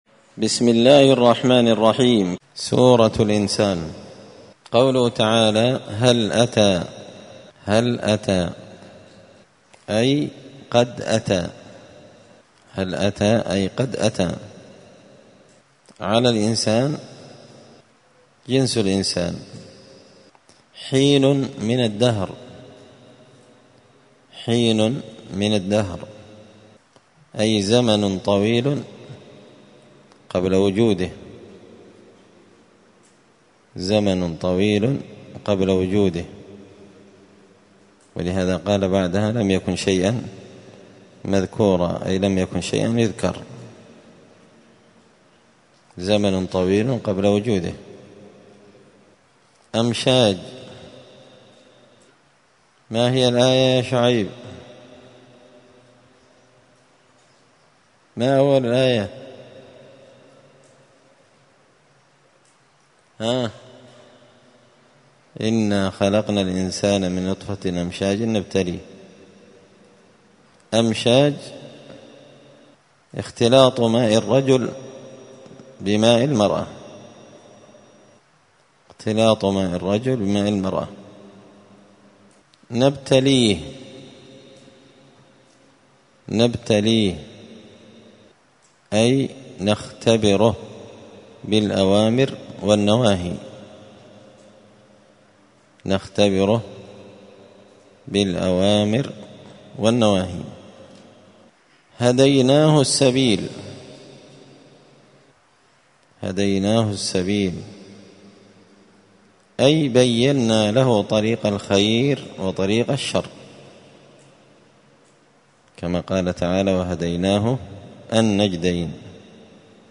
الثلاثاء 11 ربيع الأول 1445 هــــ | الدروس، دروس القران وعلومة، زبدة الأقوال في غريب كلام المتعال | شارك بتعليقك | 29 المشاهدات